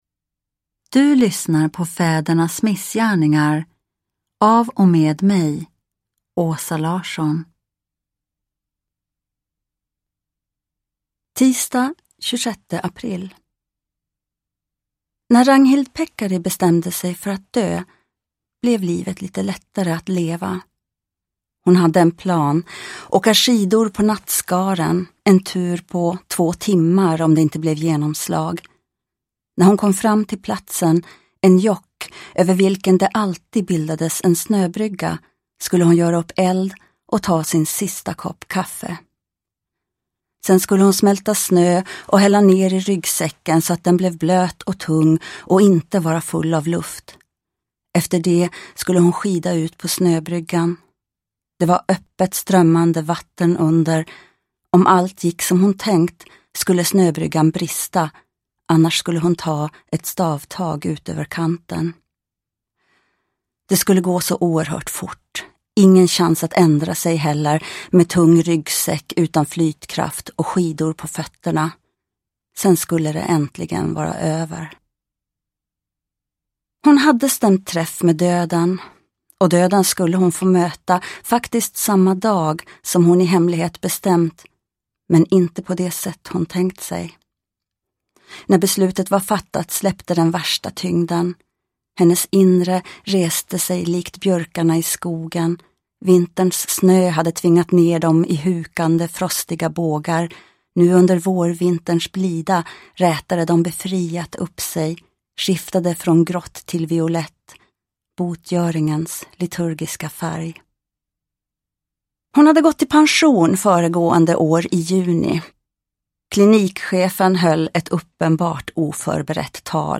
Fädernas missgärningar – Ljudbok – Laddas ner
Uppläsare: Åsa Larsson